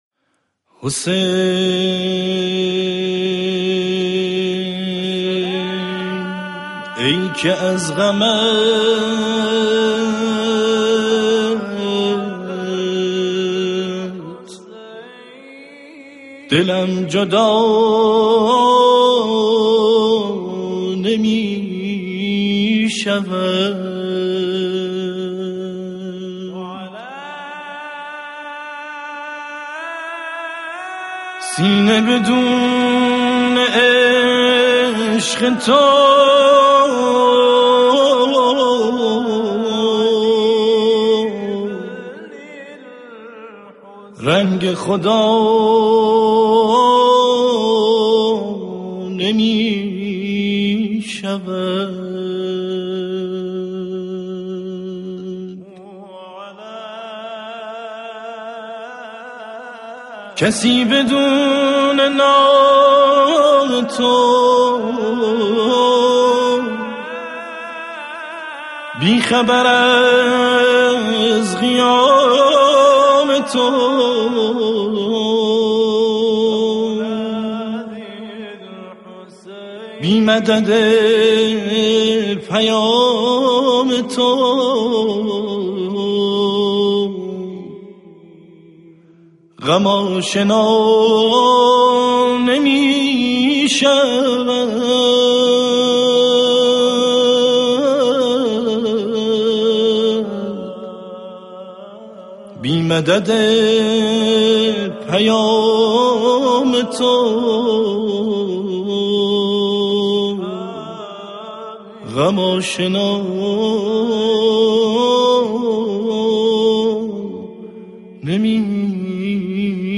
تواشیح
اثر مشترک دو گروه تواشیح